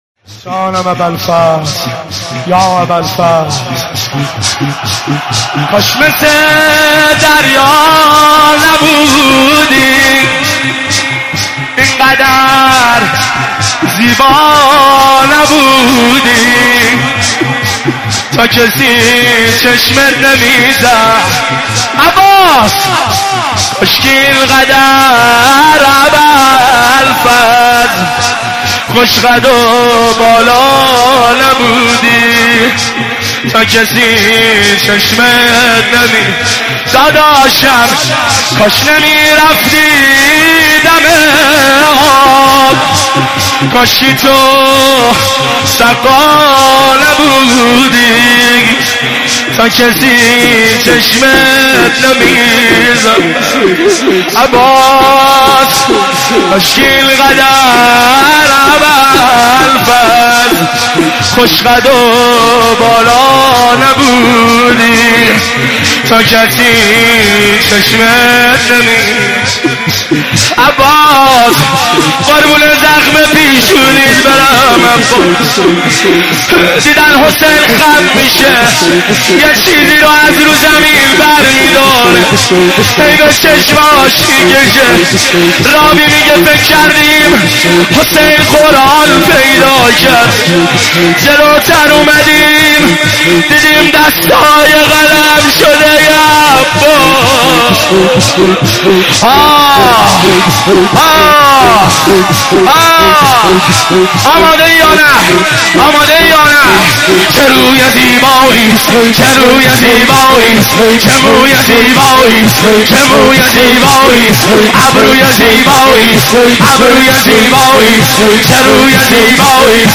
شور شب تاسوعای محرم الحرام 1390